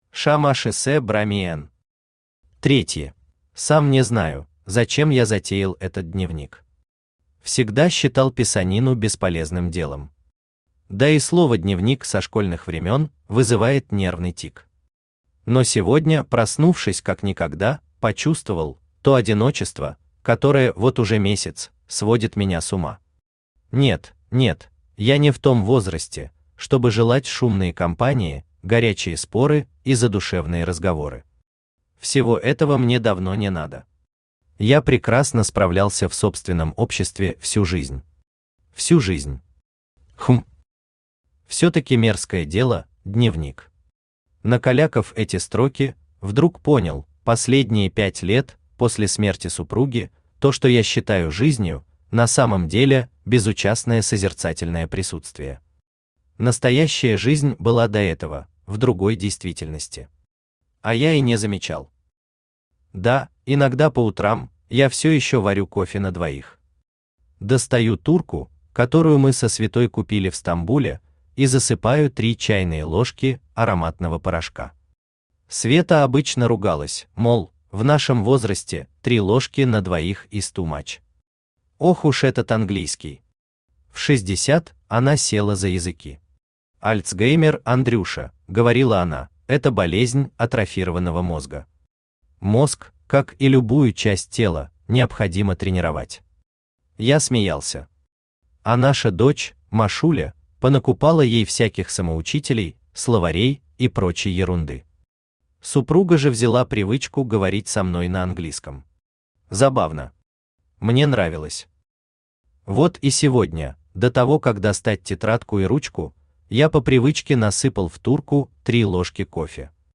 Аудиокнига Третьи | Библиотека аудиокниг
Aудиокнига Третьи Автор ШаМаШ БраМиН Читает аудиокнигу Авточтец ЛитРес.